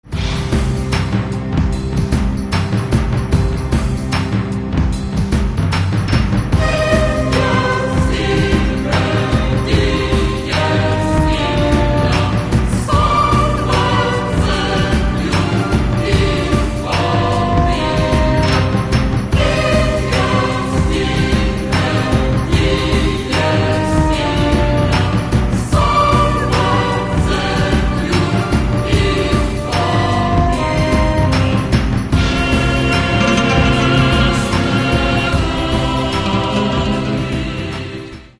Каталог -> Классическая -> Нео, модерн, авангард
Внутри нее – просторно и легко.